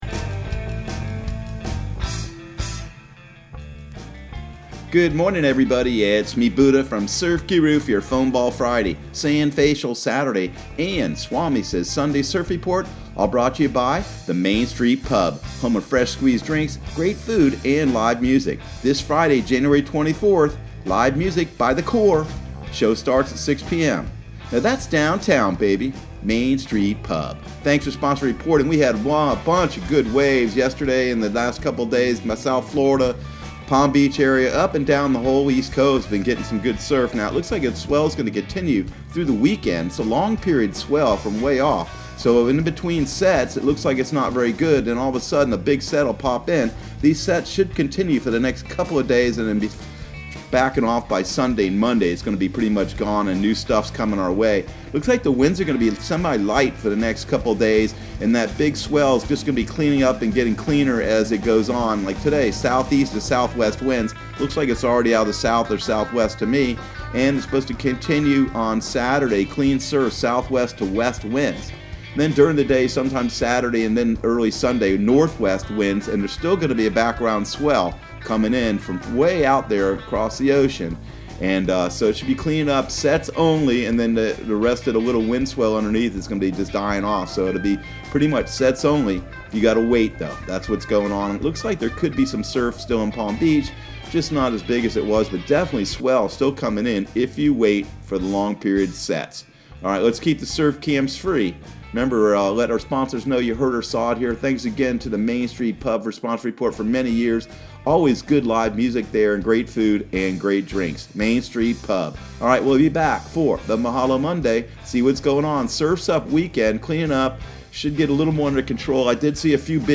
Surf Guru Surf Report and Forecast 01/24/2020 Audio surf report and surf forecast on January 24 for Central Florida and the Southeast.